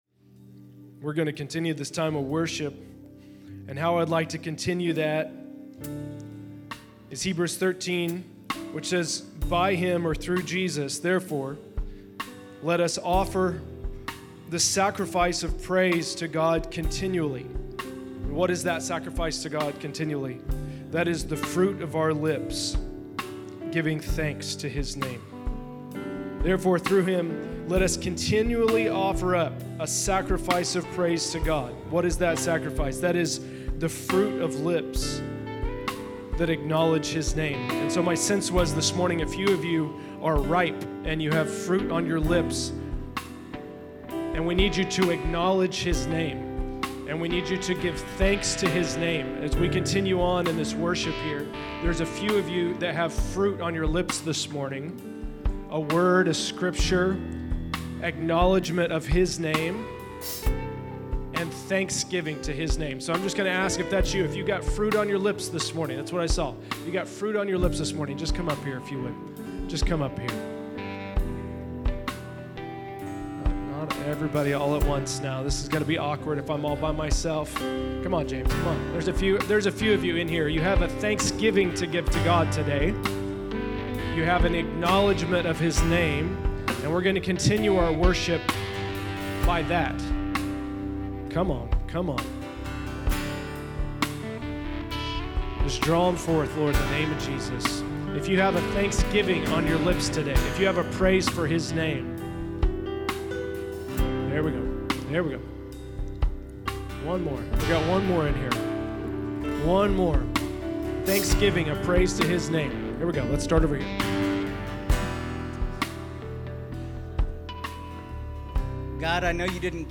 June 11, 2023      |     By: El Dorado Equip      |      Category: Testimonies      |      Location: El Dorado
Several from Equip follow worship with sacrifice of praise - the fruit of their lips! Praising and acknowledging the Name of the Lord.